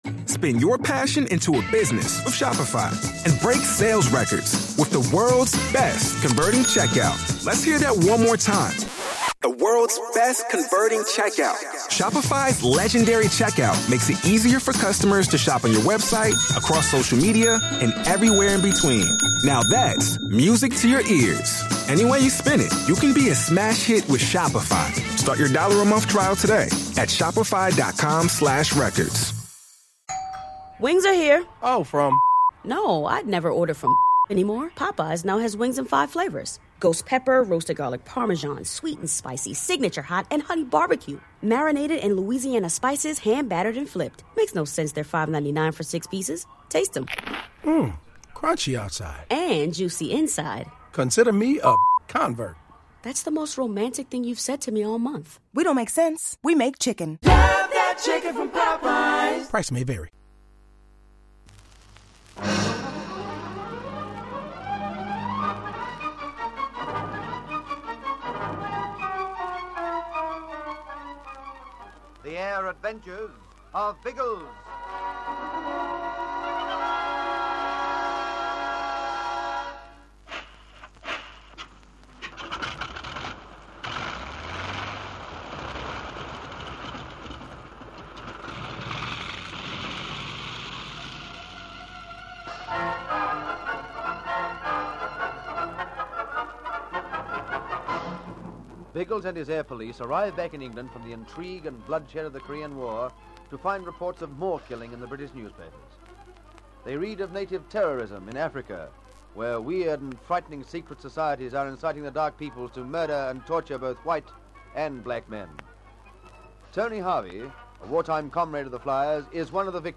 The Air Adventures of Biggles was a popular radio show that ran for almost a decade in Australia, from 1945 to 1954.
Biggles and his trusty companions, Ginger Hebblethwaite and Algy Lacey, soared through the skies in a variety of aircraft, from biplanes to jet fighters, taking on villains, rescuing damsels in distress, and generally having a whale of a time. The show was known for its exciting sound